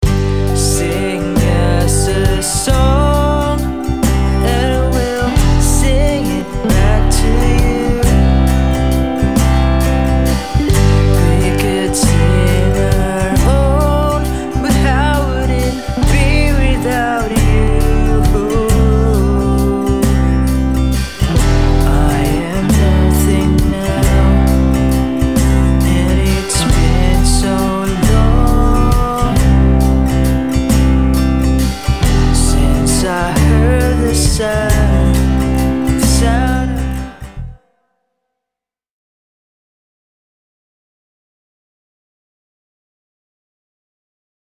I recorded a few short audio samples to demonstrate how the Maonocaster Lite AU-AM200-S1 handles recording.
In a full band mix – Recorded vocals and guitars (both acoustic and electric)
In-a-full-band-mix-Recorded-vocals-and-guitars-both-acoustic-and-electric.mp3